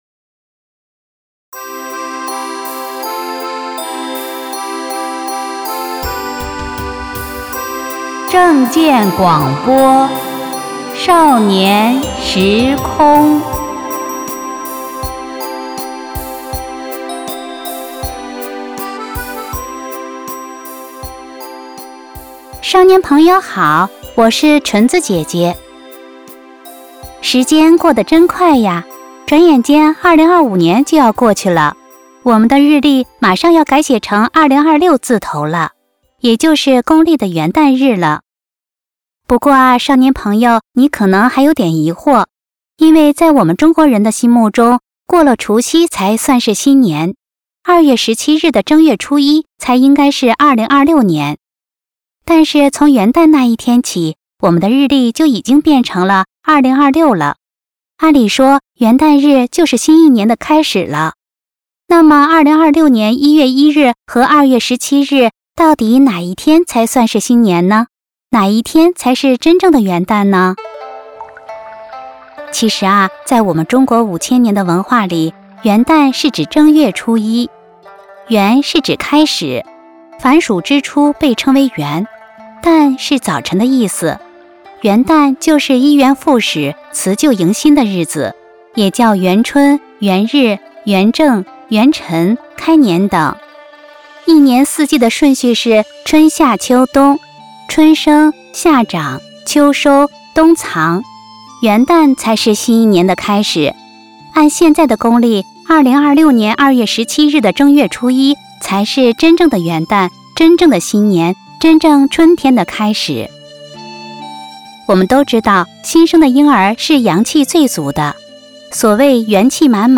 正見廣播（音頻）：少年時空78：正本清源話元旦 | 法輪大法正見網